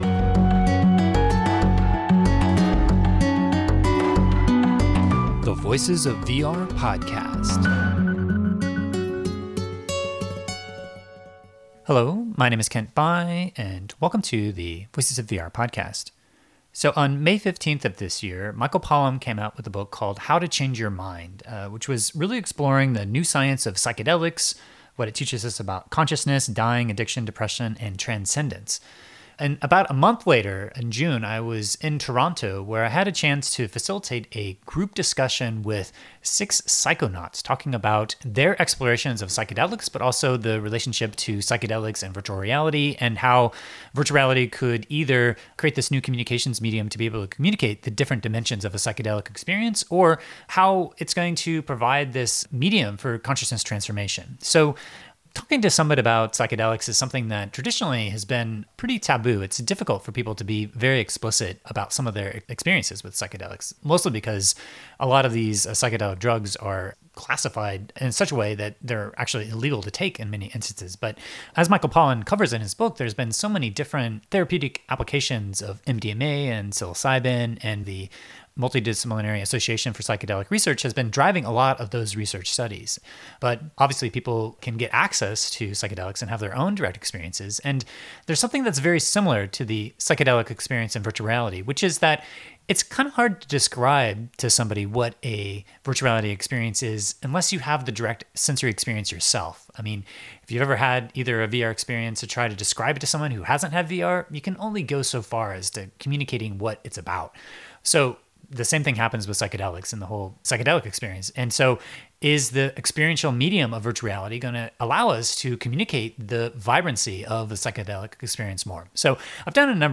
I had an opportunity to moderate a panel featuring six psychonauts who are exploring the intersection between Psychedelics, VR, and consciousness transformation at the VRTO conference in Toronto, Canada on June 16th, 2018.
Voices-of-VR-700-Psychedelic-VR-Panel.mp3